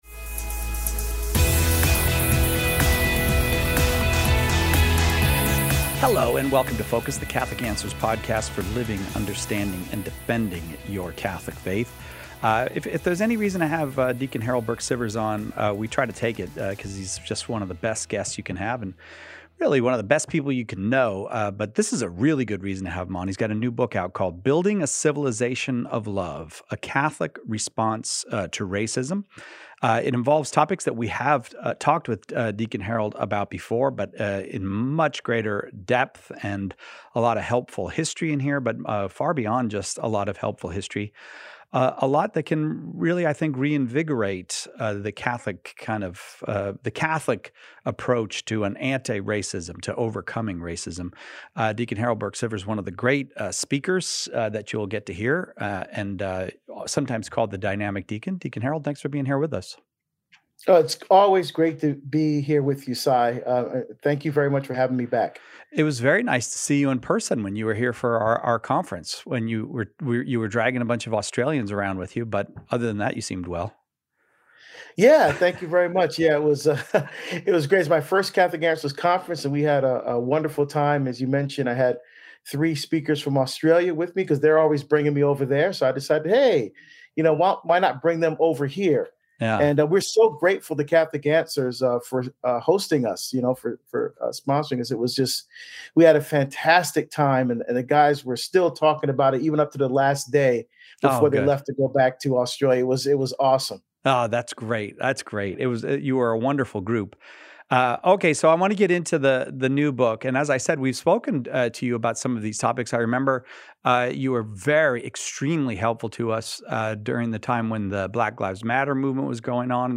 But he is also hopeful because he believes there is an answer to the evils of racial division in the Gospel of Jesus Christ. He joins us for a conversation about racism and Catholicism.